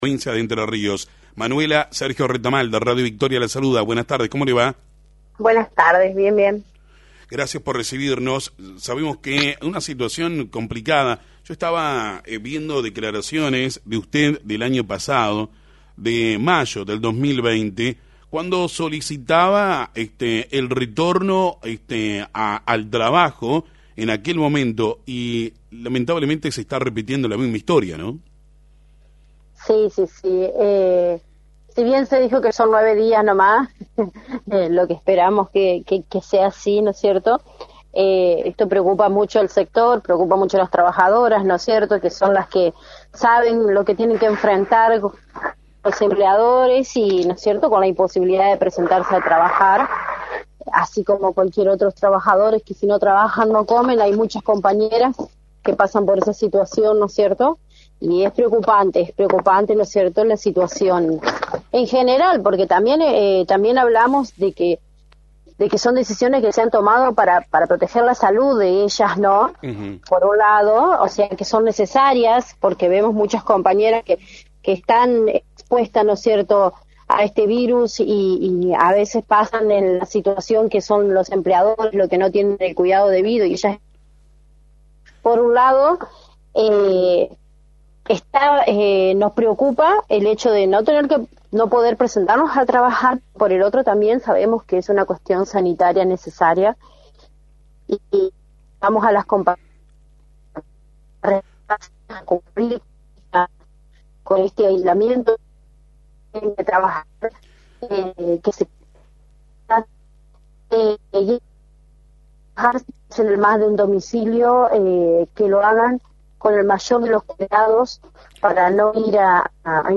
en FM 90.3